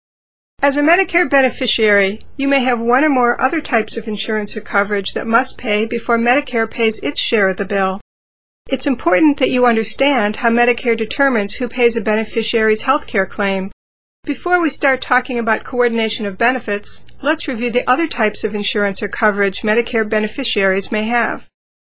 Select the Audio icon to hear Natalie begin the presentation or select the Text icon to read the script.